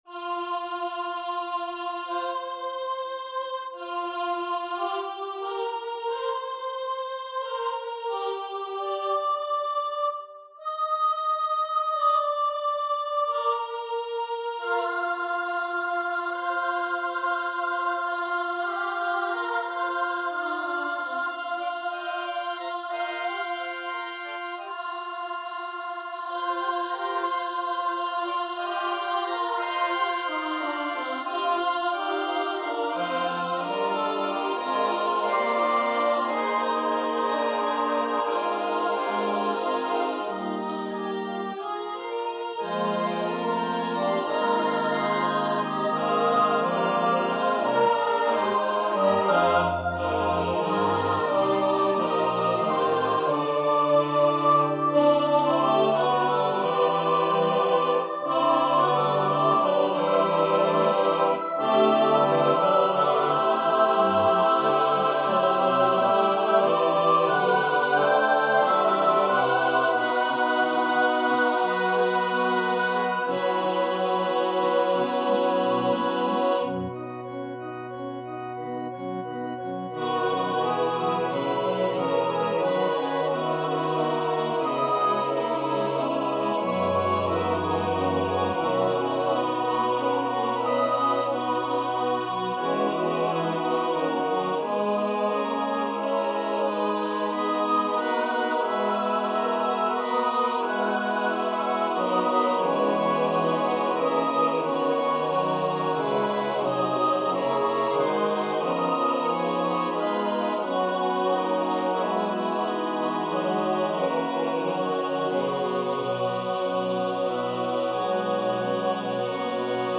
• Music Type: Choral
• Voicing: SATB, Youth Choir
• Accompaniment: Organ